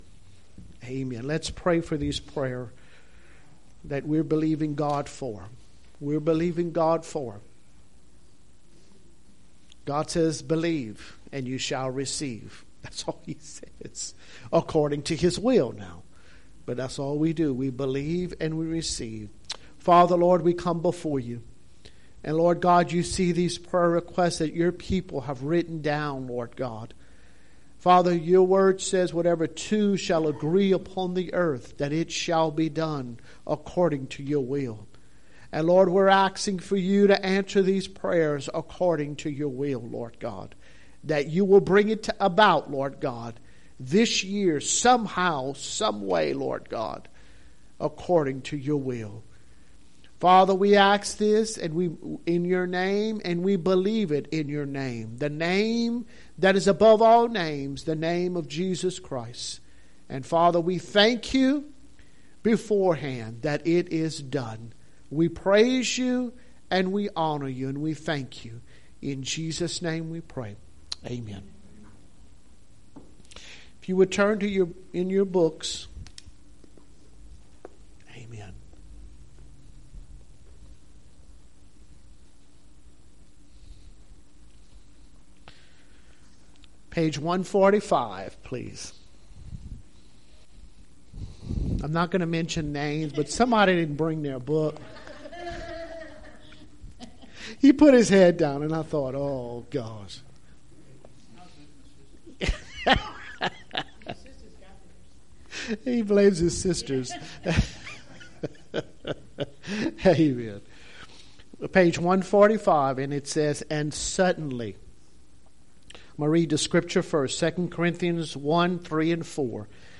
Church Sermons